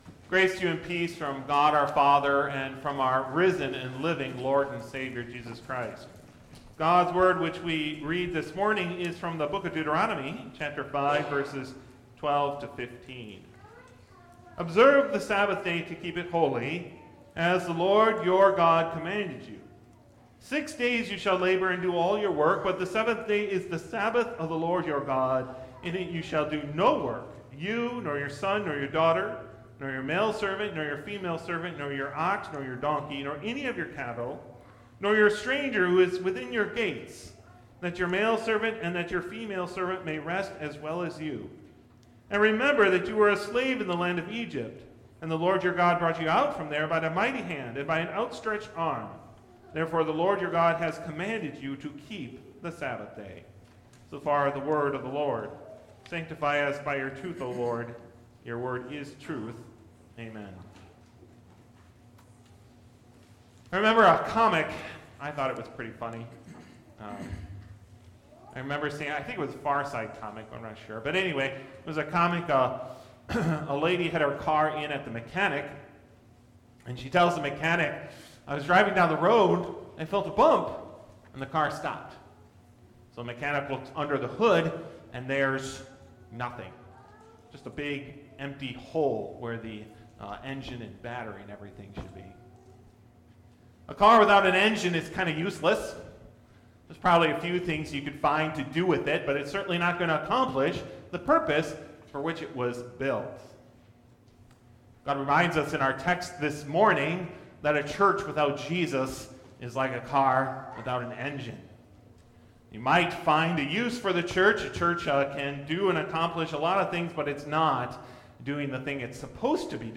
Festival: Reformation